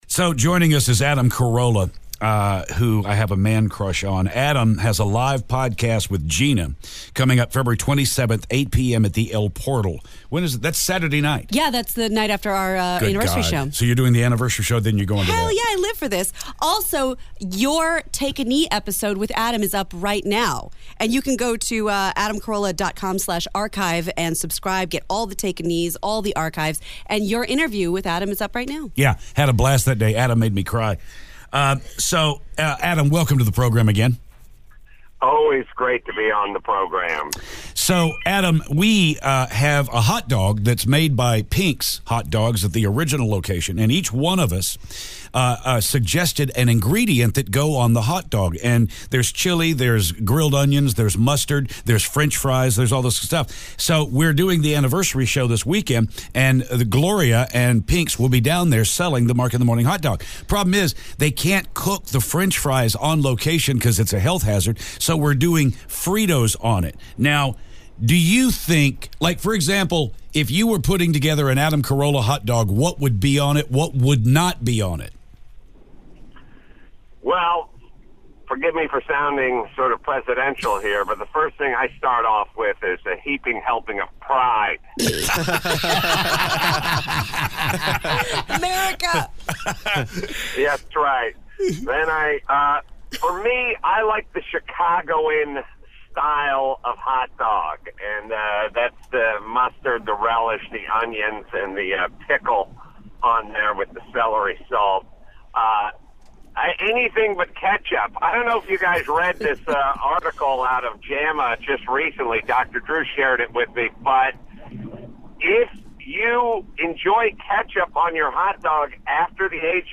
Adam Carolla calls to rant about hot dogs!